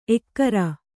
♪ ekkara